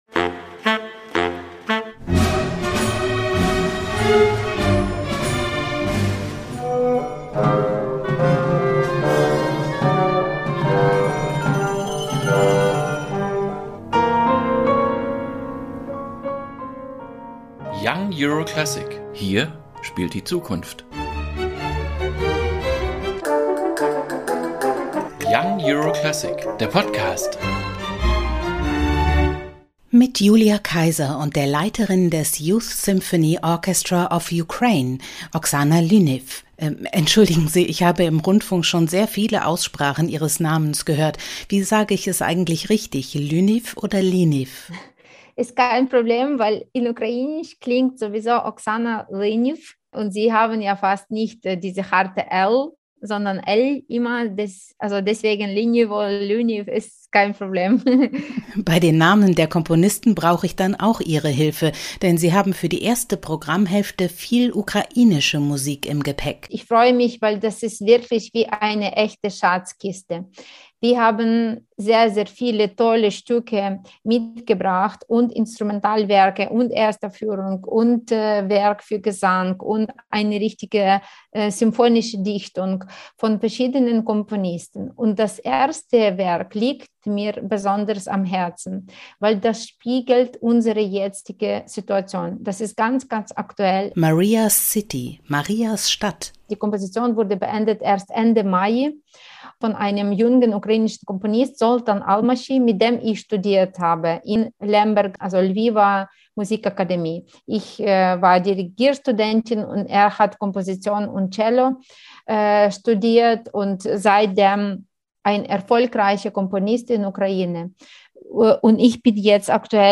Wie das weitere Programm nostalgische Sehnsucht, die Beziehung zwischen Polen und der Ukraine sowie ein kämpferisches Frauenbild aufgreift, wie Lyniv persönlich die Mammutaufgabe bewältigt, ihr Orchester durch diese harte Zeit zu bringen – und warum Patriotismus zwar schön, aber auch nicht alles ist in der Jugendorchesterszene, das und mehr erzählt die ukrainische Dirigentin in dieser Podcastfolge.